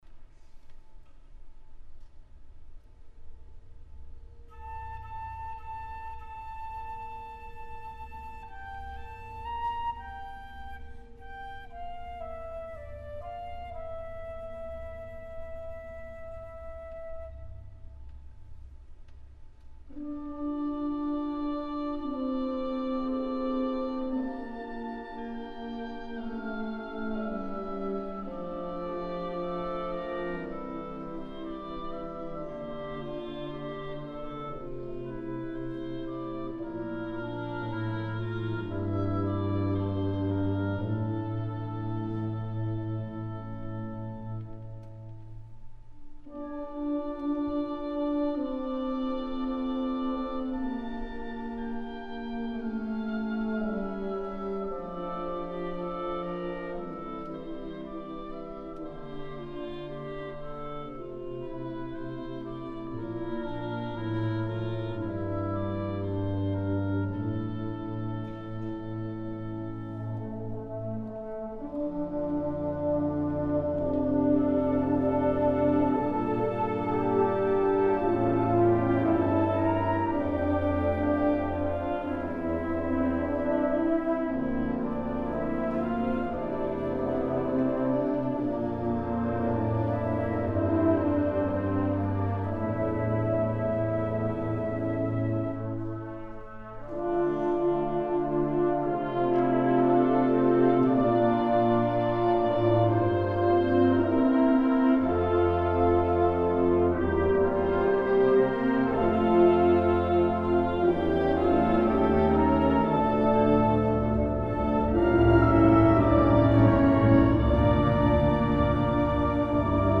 2013 UIL Symphonic Band — Liberty Band